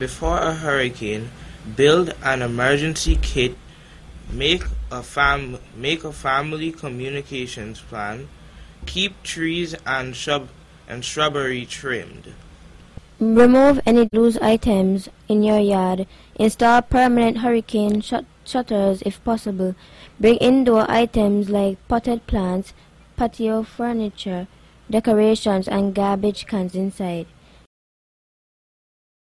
Youths share tips on Hurricane Preparedness
During the June 28th edition of the “Youth on the Go” program